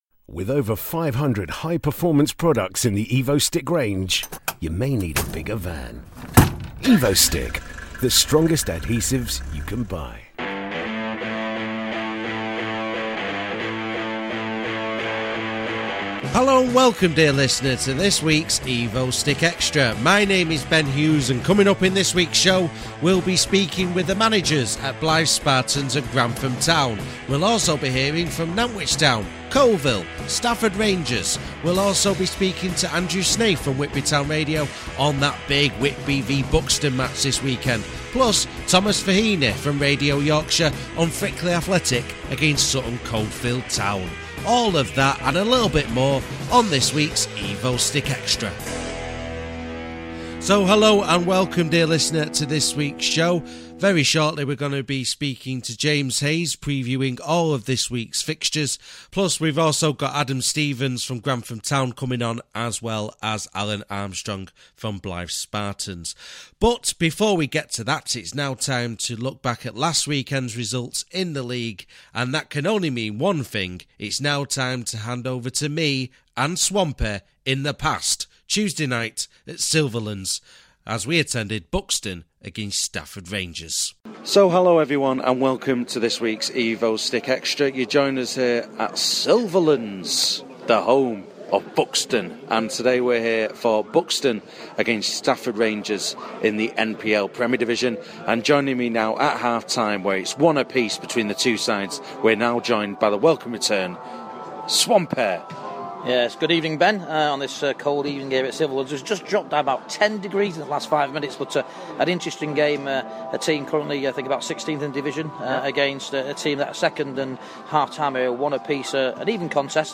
In addition we also hear the post match views of the management at Stafford Rangers, Nantwich Town and Coalville Town.